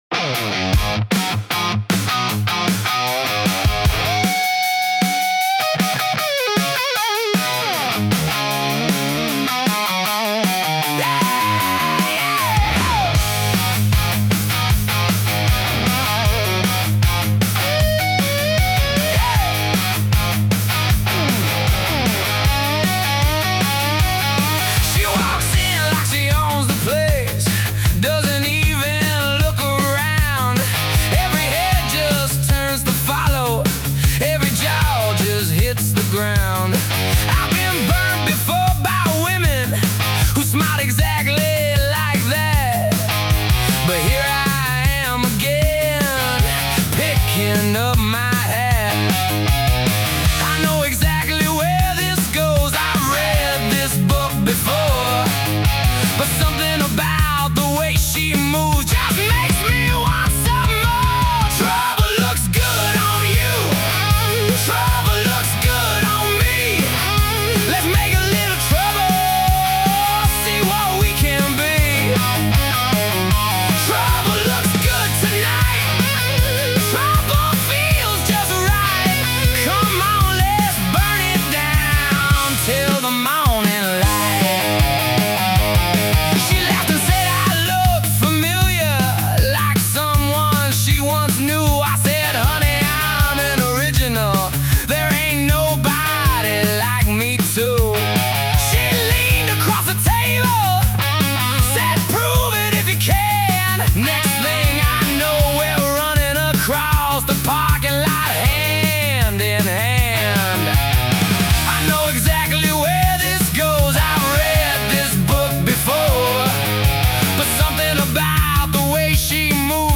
Made with Suno
hard rock, garage rock, rock and roll